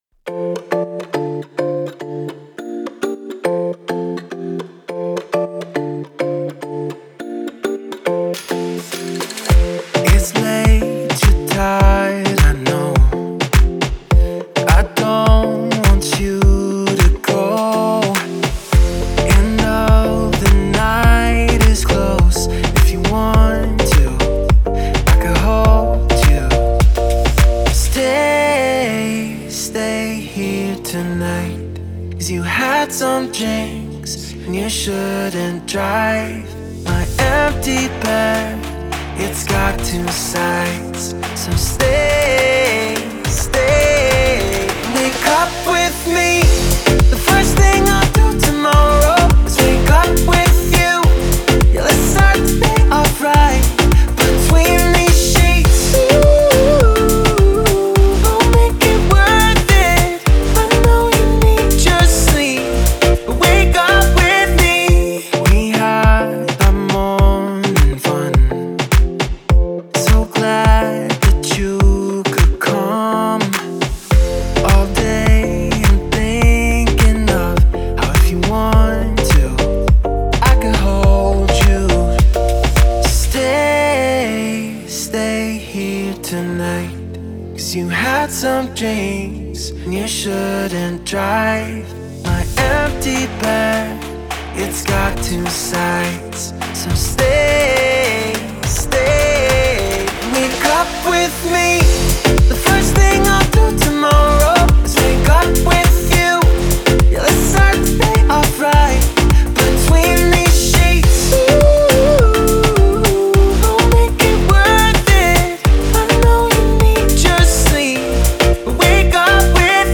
инди-поп